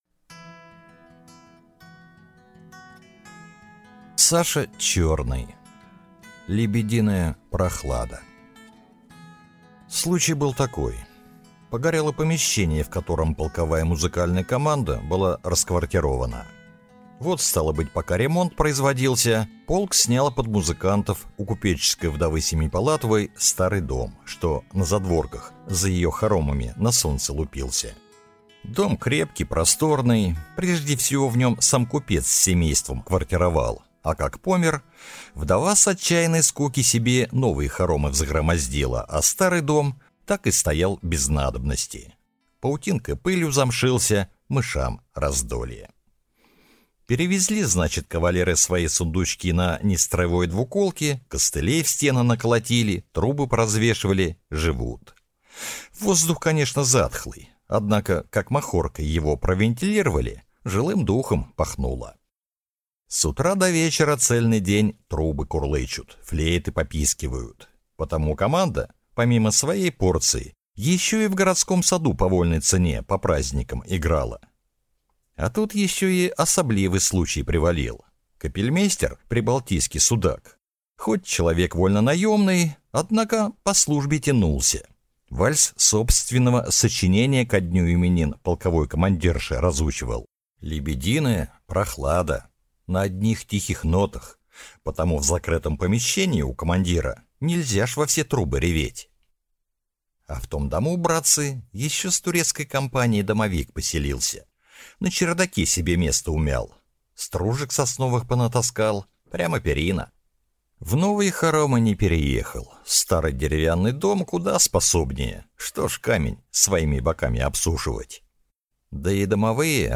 Аудиокнига «Лебединая прохлада» | Библиотека аудиокниг